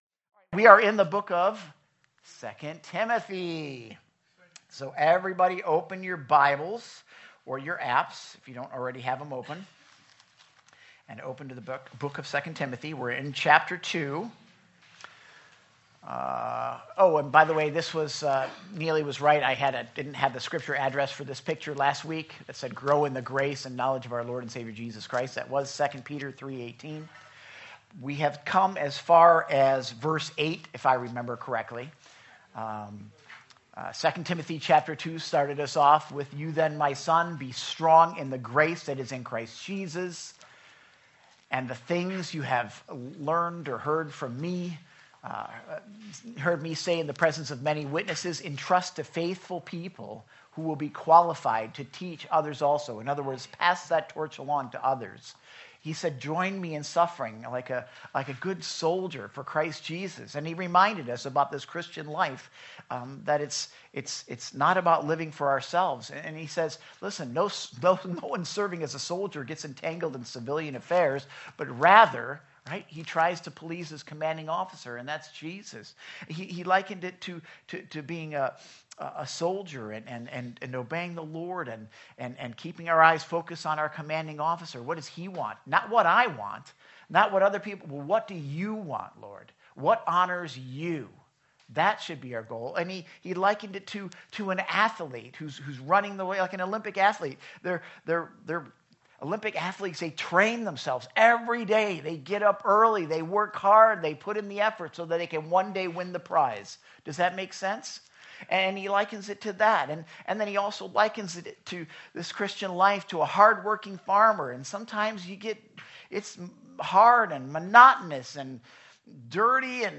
2026 Sermons